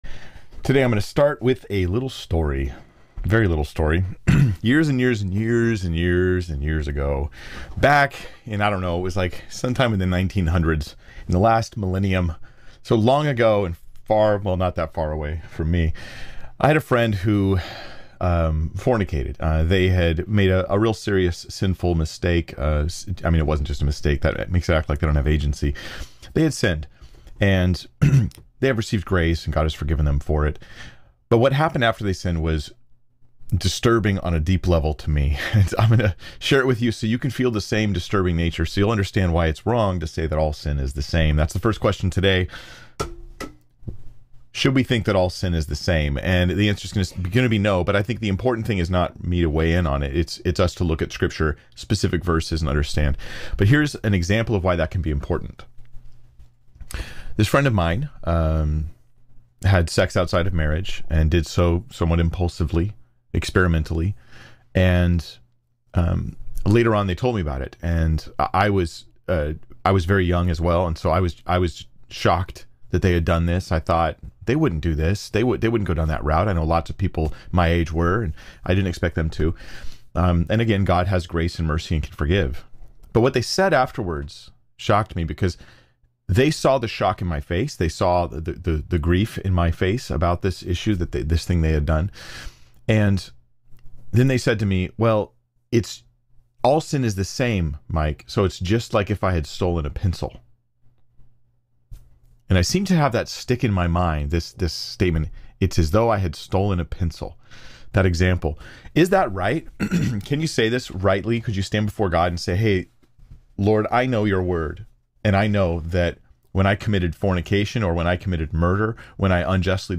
1:16:15 Play Pause 1h ago 1:16:15 Play Pause Play later Play later Lists Like Liked 1:16:15 I'm taking questions from the live chat...here are the rules: I do this live stream most Fridays at 1 p.m. Pacific Time.